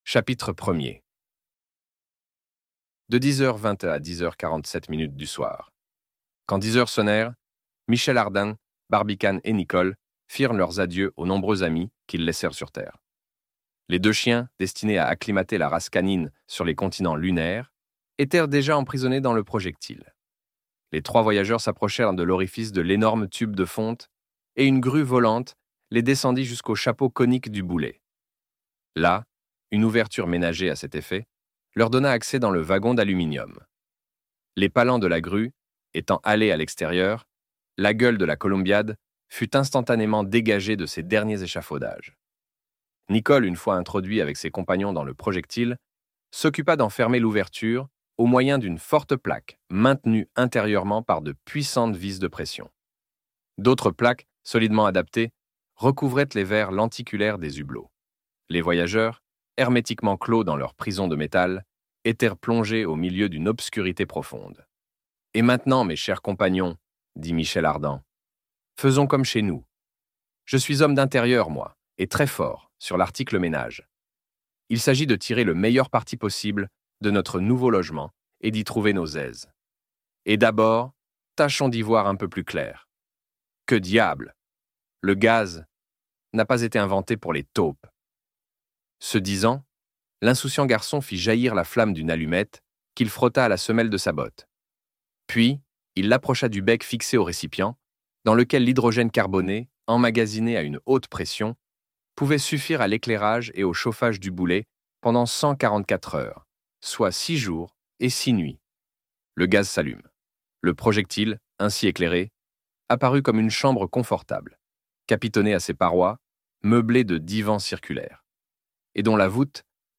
Autour de la Lune - Livre Audio